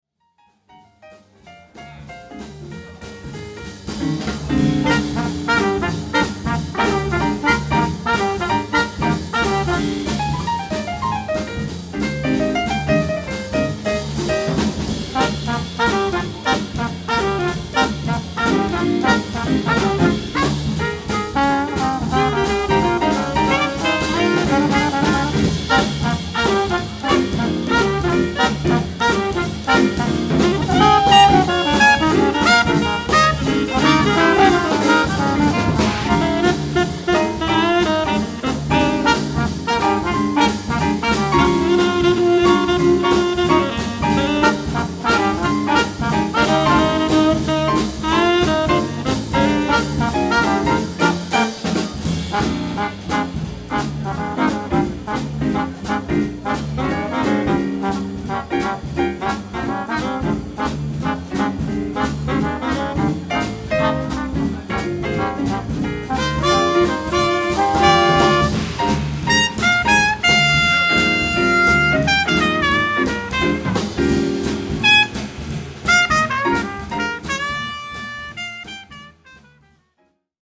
A six piece version of the full
live @ Bellevue Art Museum